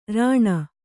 ♪ rāṇa